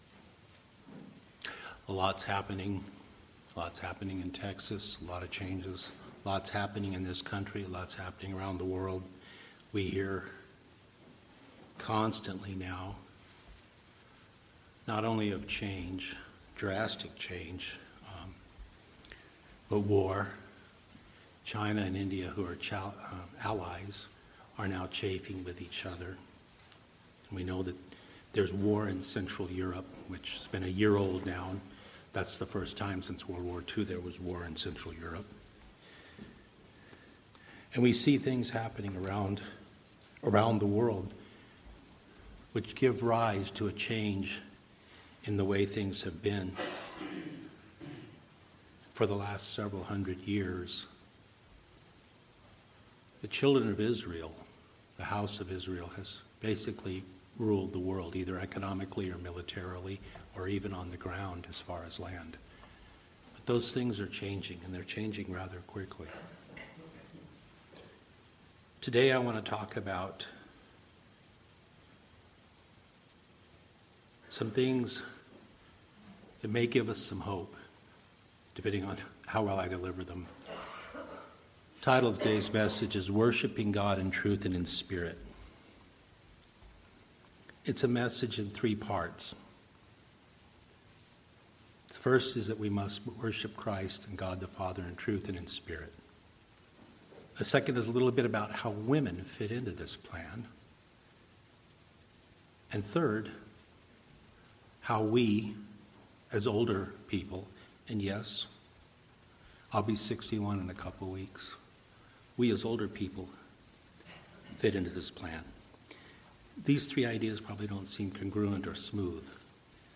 Given in Lawton, OK